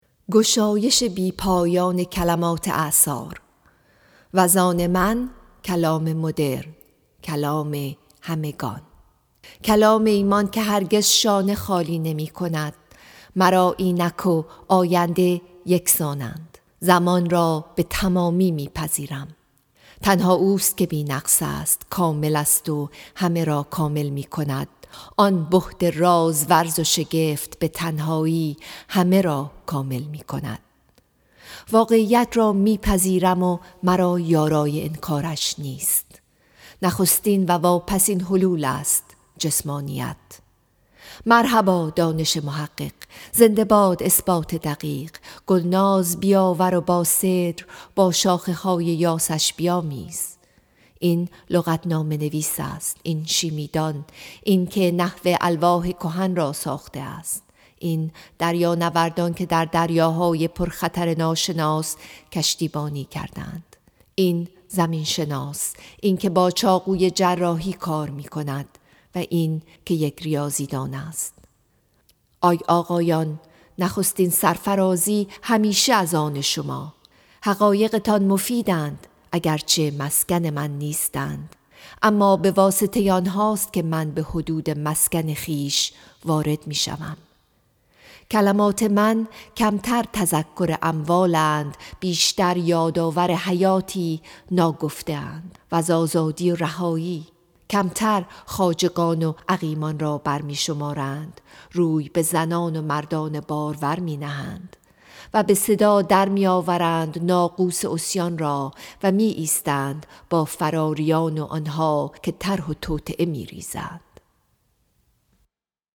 Song of Myself, Section 23 —poem read